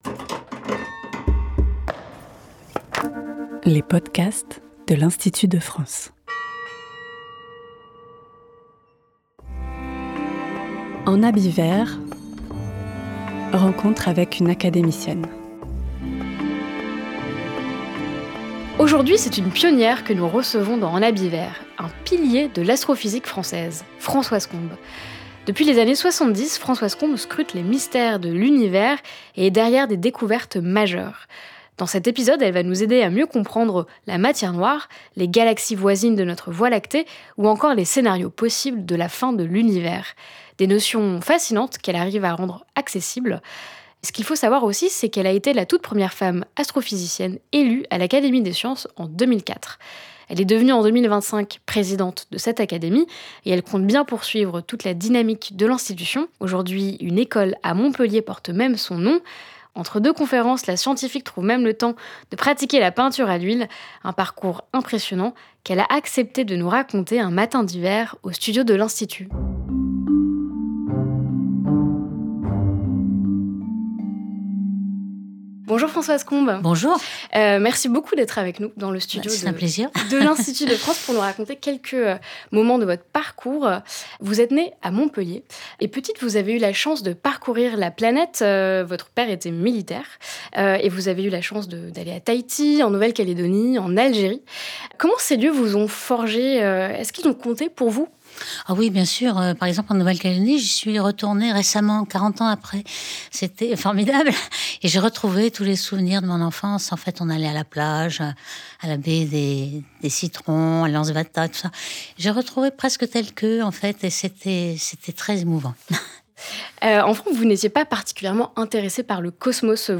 C’est par un matin d'hiver, au studio de l’Institut, qu’elle a accepté de partager son cheminement avec nous.